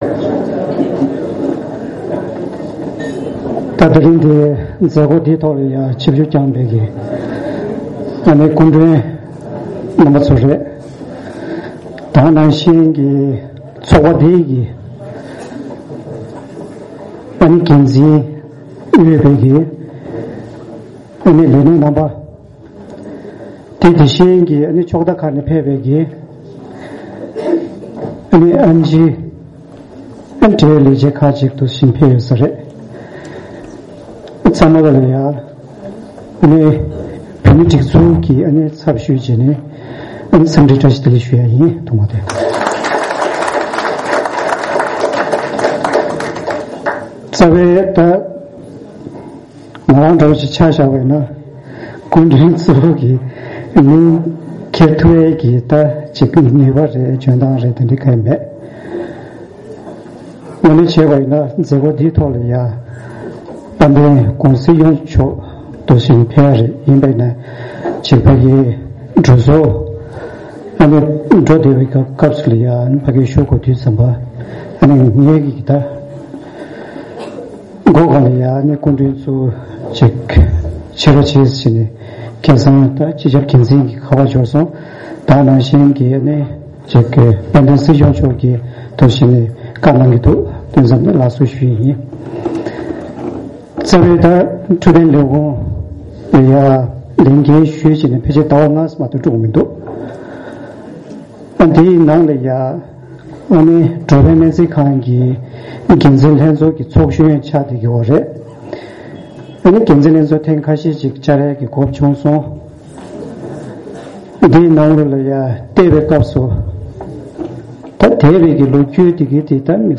ཕྱི་ལོ་ ༢༠༢༣ ཟླ་ ༣ ཚེས་ ༢༣ ཉིན་སྨན་རྩིས་མཐོ་སློབ་ཚོགས་ཁང་དུ་ྋརྒྱལ་དབང་སྐུ་ཕྲེང་ ༡༣ པ་ཆེན་པོས་ལྷ་ལྡན་སྨན་རྩིས་ཁང་ཕྱག་བཏབ་ནས་ལོ་ངོ་ ༡༠༧ དང་། ྋརྒྱལ་དབང་སྐུ་ཕྲེང་ ༡༤ པ་ཆེན་པོས་བཞུགས་སྒར་དྷ་སར་འགྲོ་ཕན་སྨན་རྩིས་ཁང་གསར་འཛུགས་བསྐྱངས་ནས་ལོ་ངོ་ ༦༢ ཧྲིལ་པོ་འཁོར་བའི་དུས་དྲན་སྲུང་བརྩི་མཛད་སྒོ་ཚོགས་གནང་སོང་།
Chief Guest  Secretary  DoH Kungo Dawa Tsultrim Adress.mp3